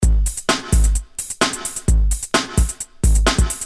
Free MP3 electro drumloops soundbank 2
Electro rythm - 130bpm 24